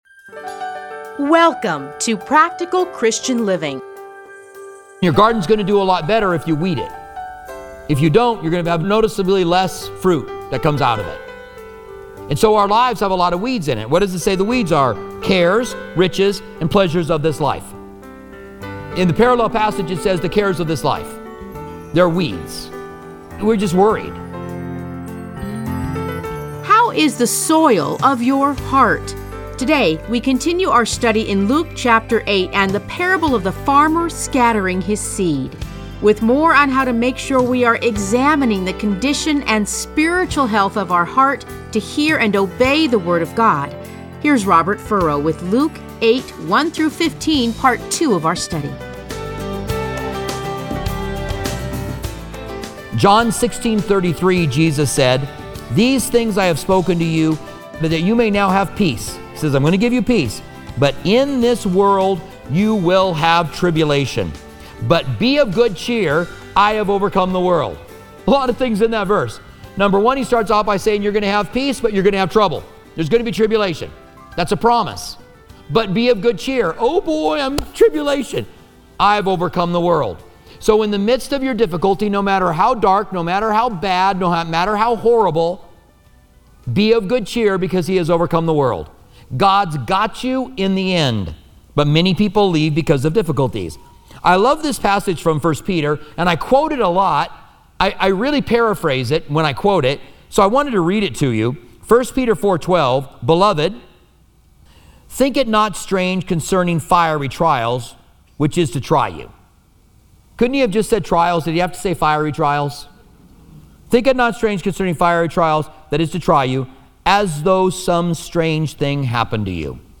Listen to a teaching from Luke 8:1-15.